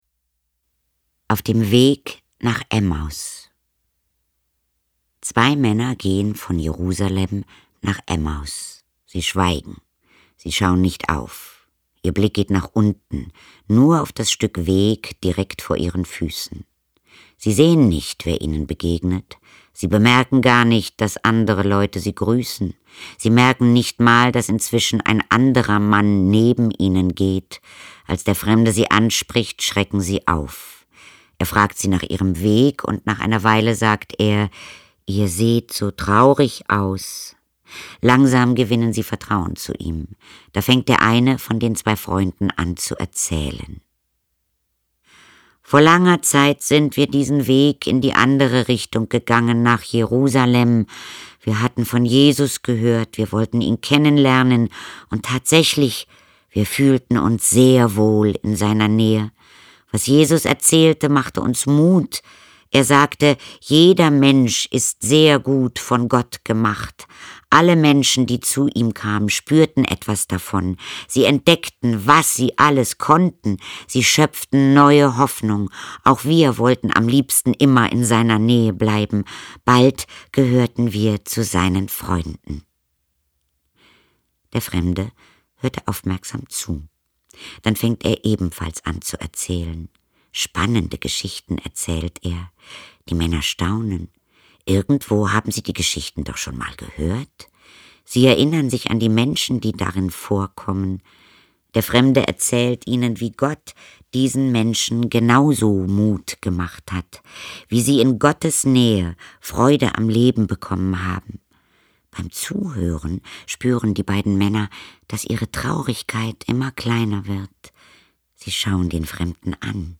Die erfolgreiche, neue Hörbuchreihe mit Geschichten aus der Bibel für Kinder.